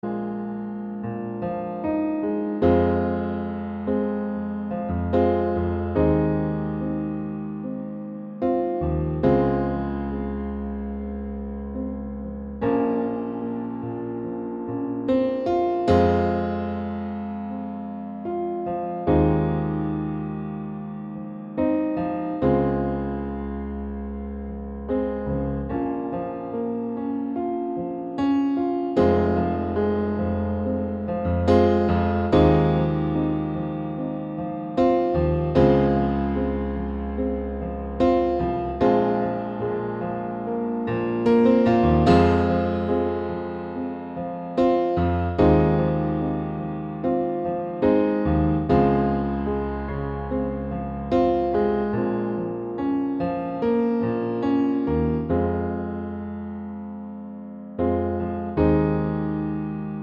Piano Only Version Pop (2010s) 5:38 Buy £1.50